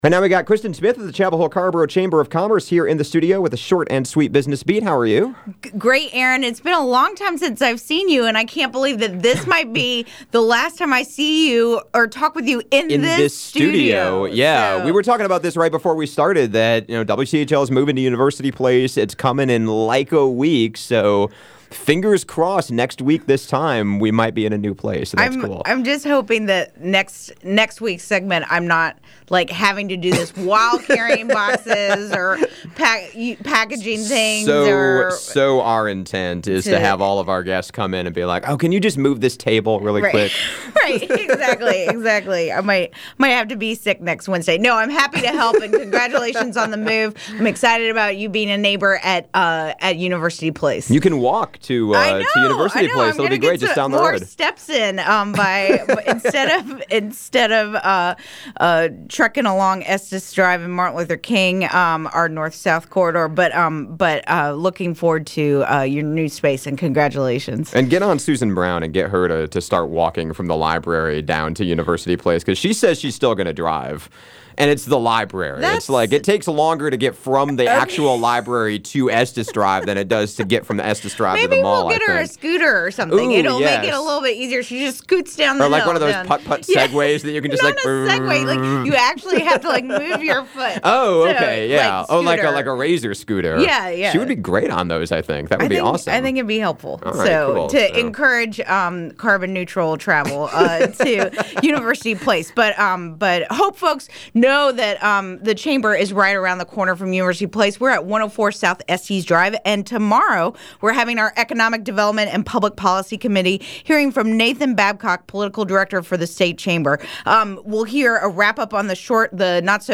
The last Short and Sweet Business Beat…in our current studio, that is.